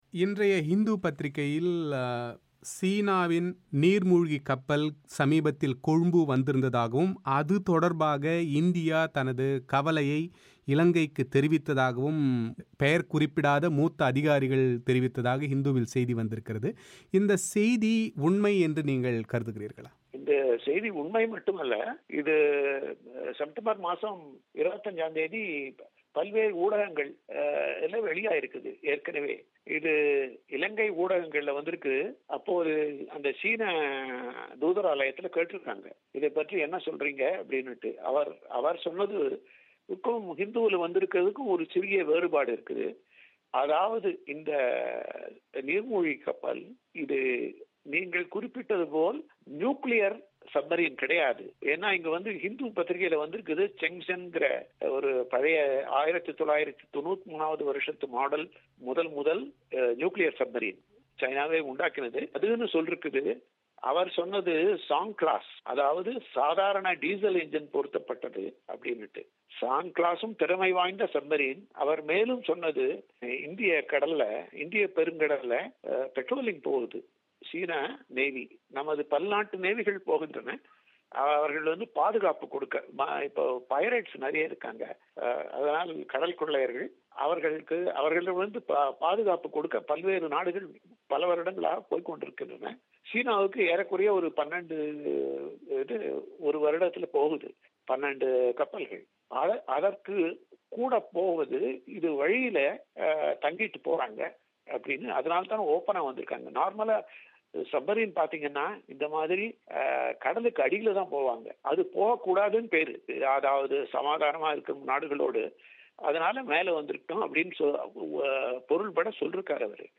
பிபிசி தமிழோசைக்கு அளித்த செவ்வியின் விரிவான ஒலி வடிவத்தை நேயர்கள் இங்கே கேட்கலாம்.